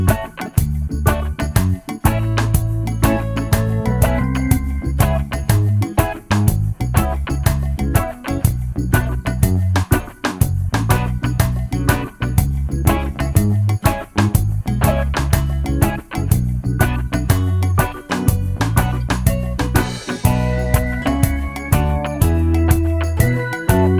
minus lead guitar no Backing Vocals Reggae 3:59 Buy £1.50